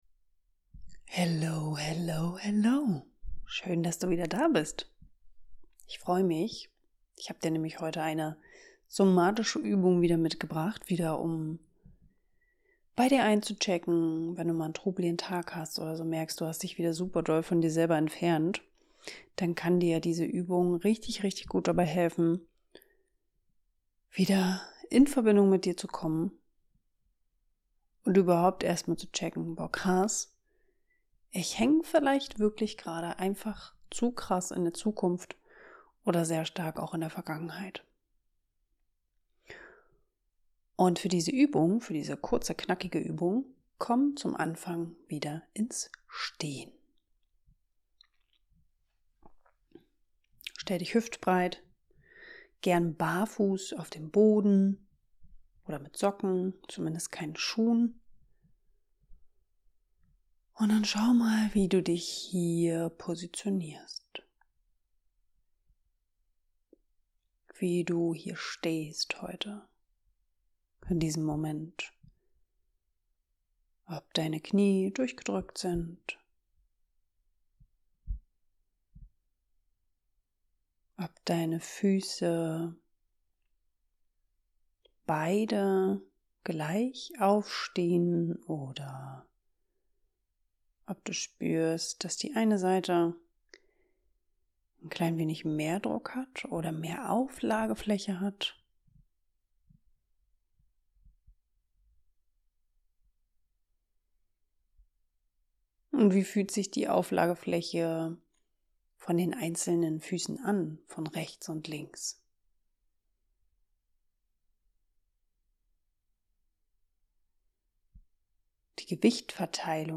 Diesmal habe ich die Folge ohne Musik dir eingesprochen.